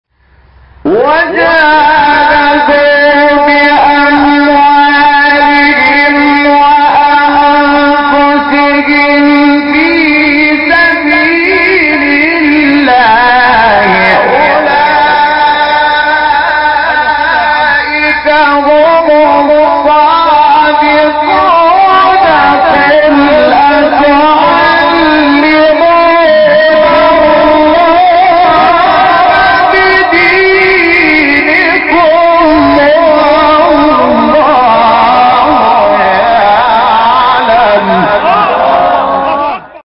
آیه 15-16 سوره حجرات استاد محمود شحات | نغمات قرآن | دانلود تلاوت قرآن